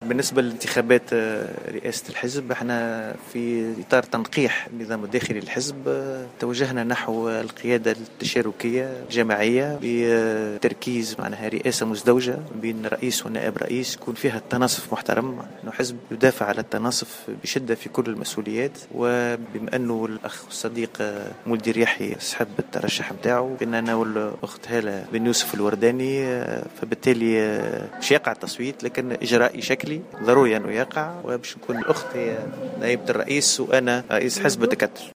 صرّح الوزير الأسبق والناشط السياسي خليل الزاوية لمراسلة "الجوهرة أف أم"